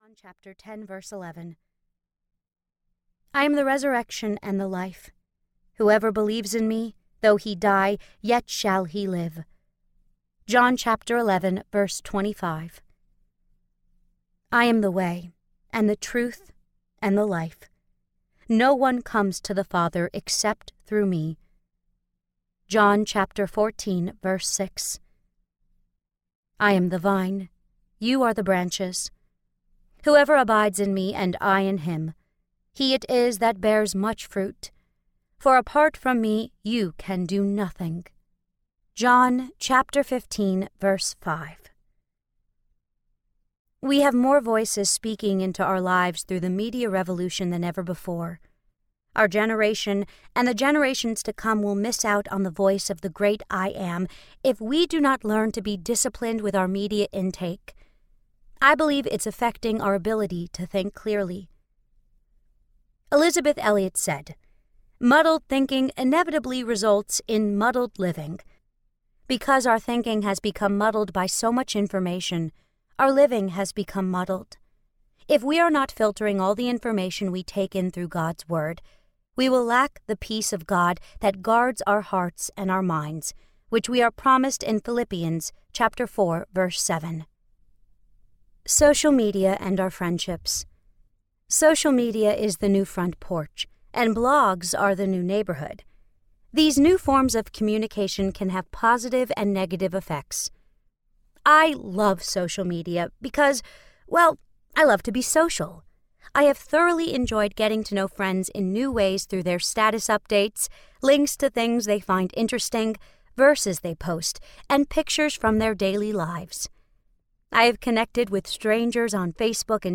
Women Living Well: Find Your Joy in God, Your Man, Your Kids, and Your Home Audiobook
Narrator